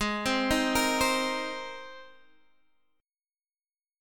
Abadd9 Chord